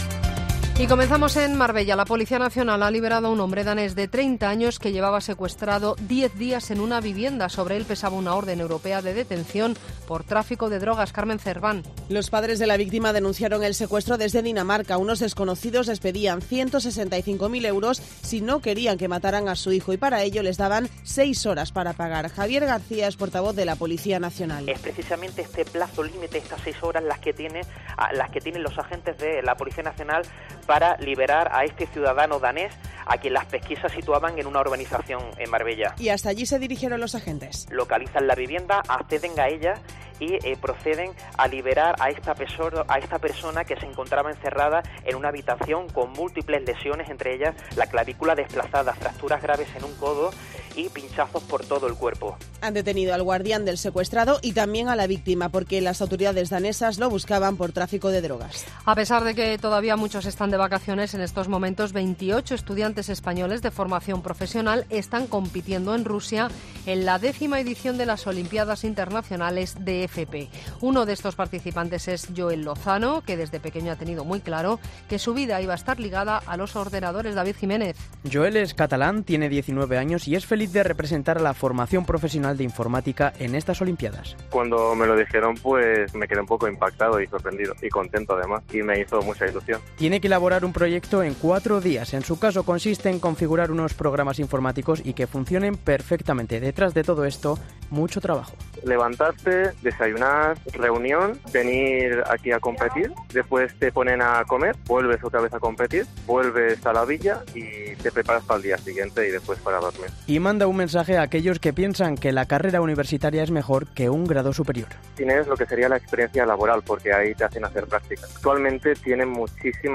Boletín de noticias de COPE del 23 de agosto de 2019 a las 21.00 horas